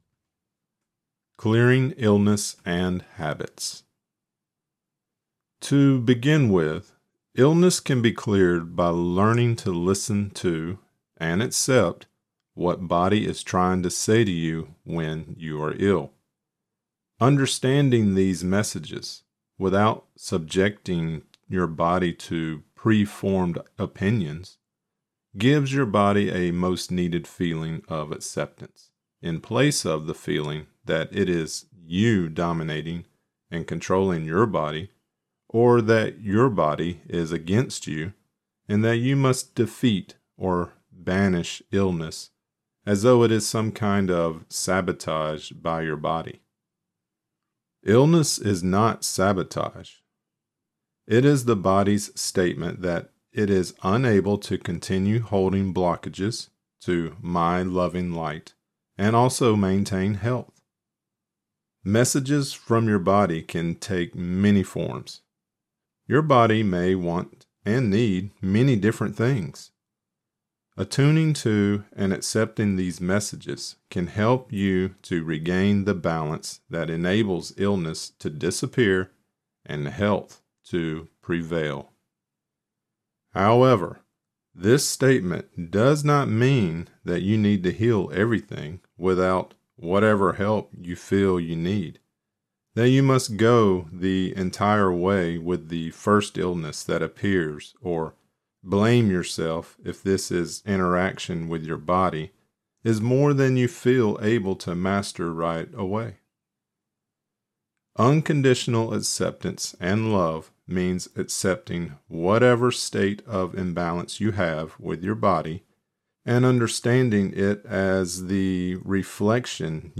This is part 3 of the Right Use of Will and this lecture includes: Clearing Illness and Habits.